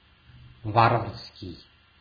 Ääntäminen
IPA : /ˈsævɪdʒ/